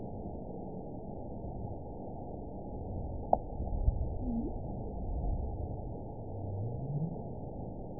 event 917332 date 03/28/23 time 02:09:56 GMT (2 years, 1 month ago) score 9.55 location TSS-AB01 detected by nrw target species NRW annotations +NRW Spectrogram: Frequency (kHz) vs. Time (s) audio not available .wav